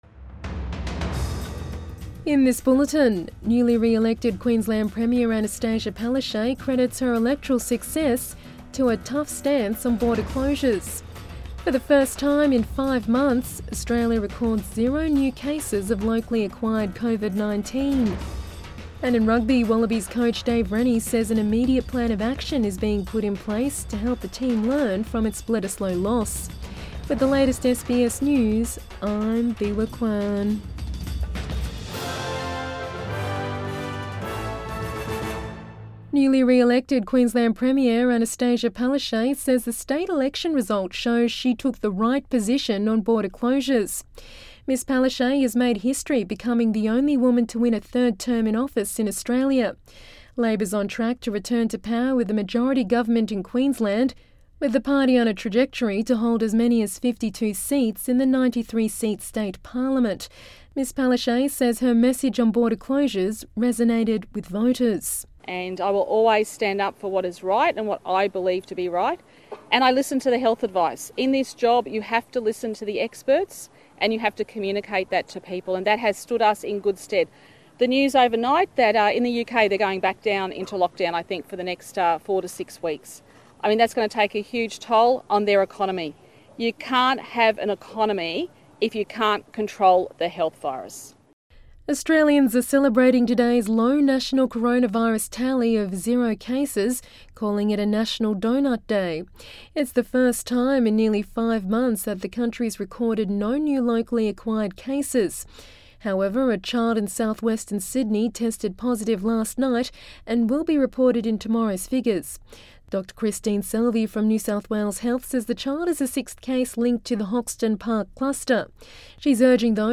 PM bulletin 1 November 2020